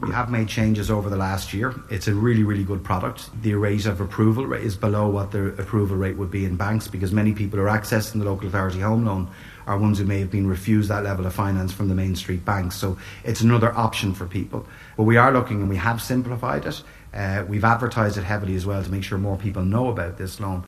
Housing Minister Darragh O’Brien says they’ve reviewed the application process to make it more accessible……..